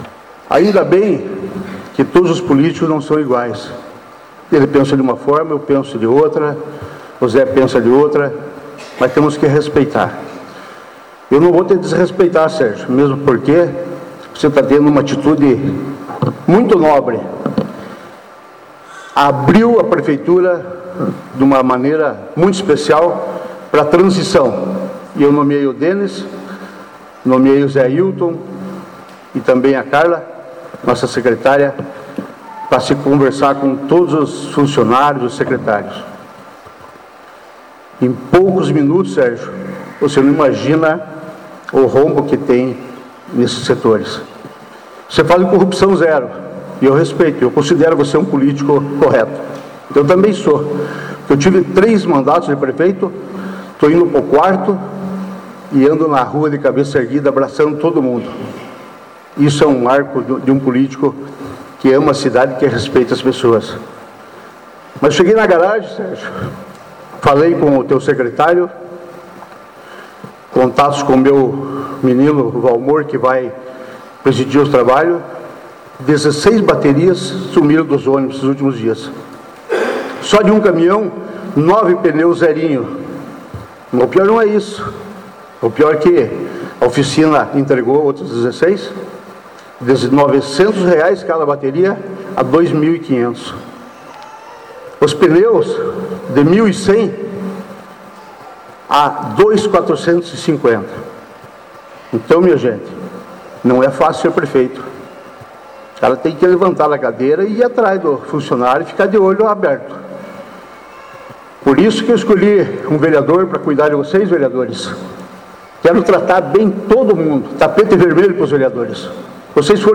Na manhã do primeiro dia de 2025, no Cine Teatro Municipal, com lotação completa, aconteceu sessão solene de posse dos eleitos em 6 de outubro para a Prefeitura e a Câmara Municipal de Palmeira.
Foi então que o prefeito empossado usou da palavra para falar sobre a posse, fazer agradecimentos, destacar a transição de governo, o apoio de familiares e o trabalho de aliados e de apoiadores na campanha eleitoral, além de fazer contraponto a alguns pontos destacados pelo ex-prefeito.